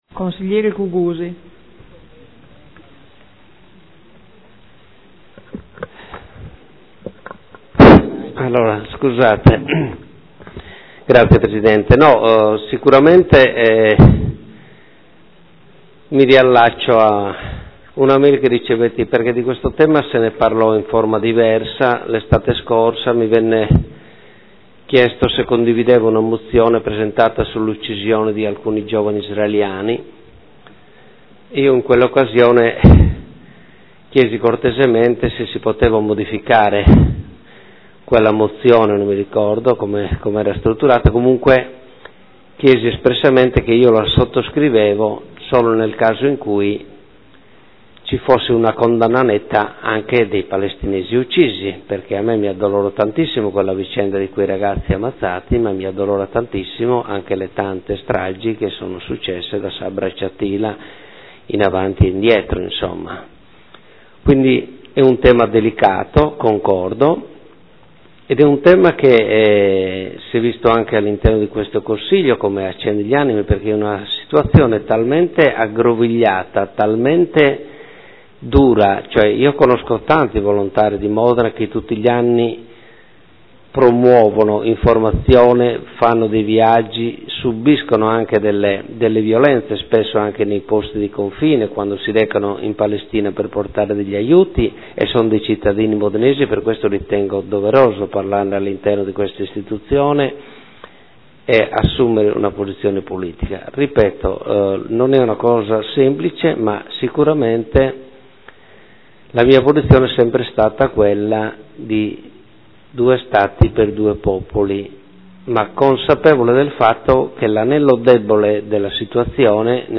Seduta del 21/05/2015 dibattito mozioni 27236 e 58705 sulla Palestina.